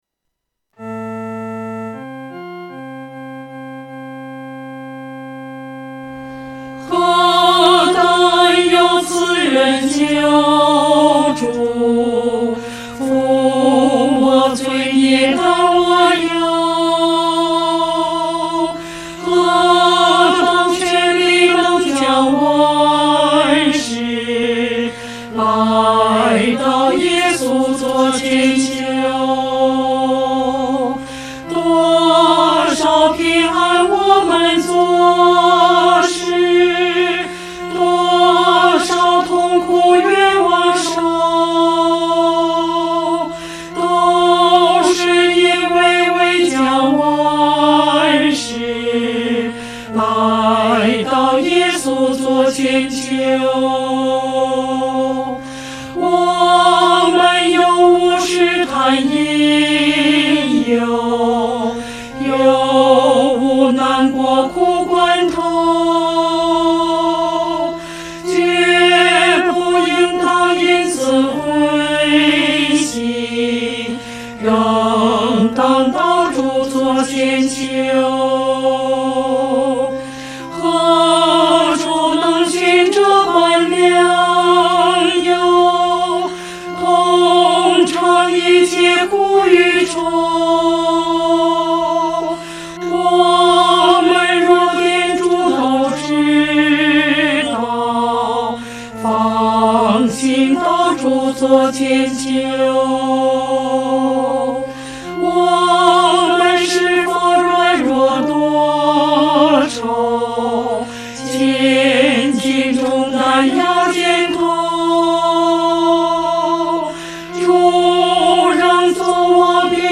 合唱 （全首）
女低